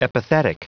Prononciation du mot epithetic en anglais (fichier audio)
Prononciation du mot : epithetic